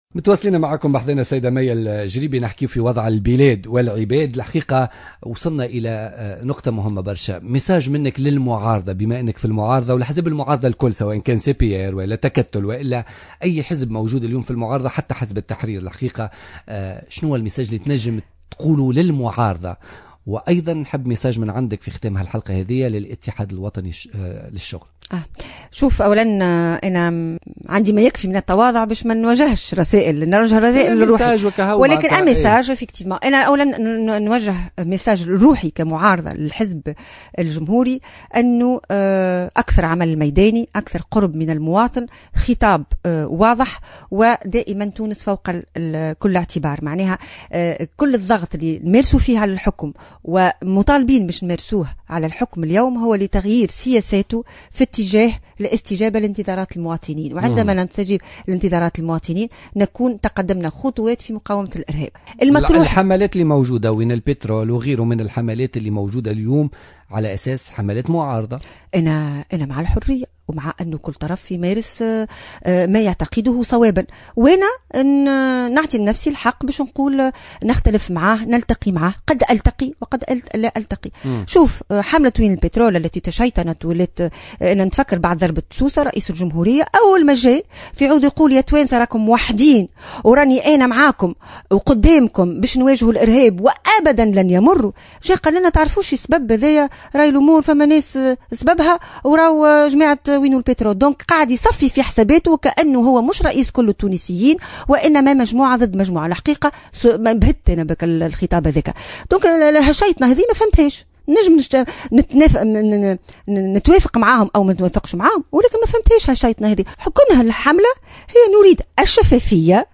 أكدت الأمينة العامة للحزب الجمهوري مية الجريبي ضيفة برنامج بوليتيكا اليوم...